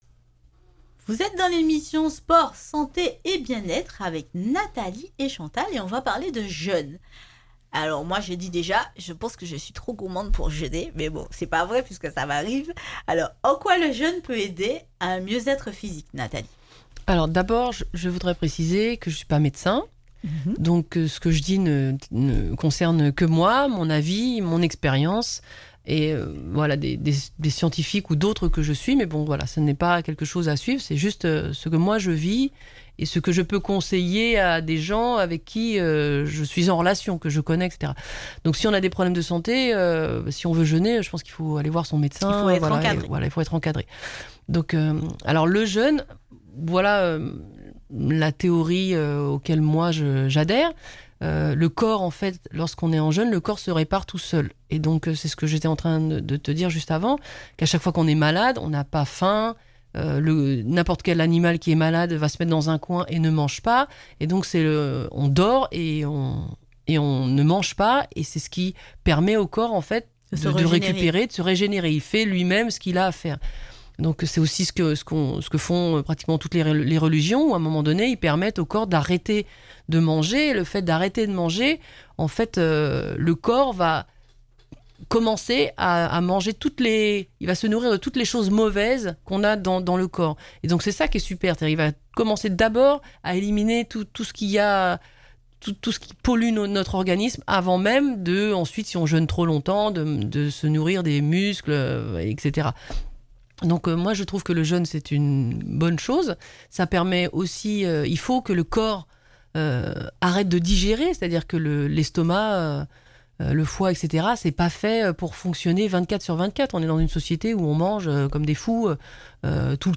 coach sportif professionnel